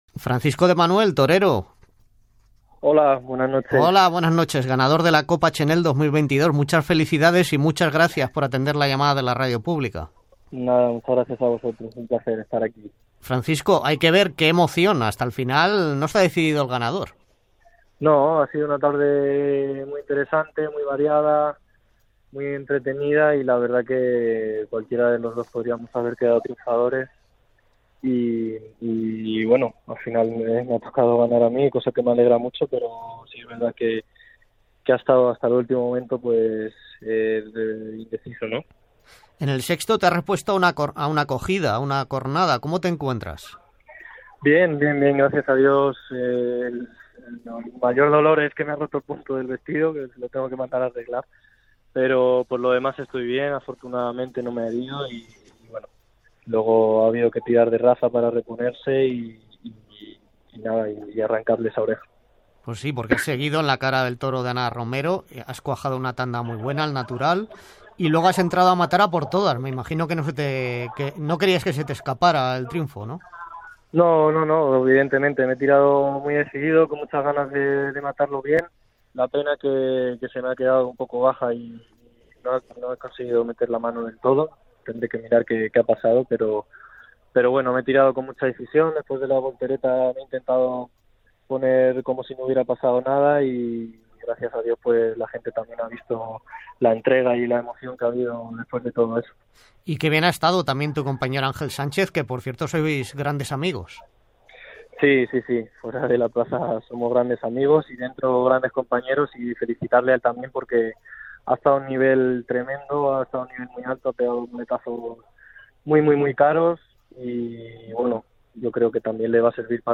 Entrevista
Informatiu